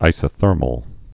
(īsə-thûrməl)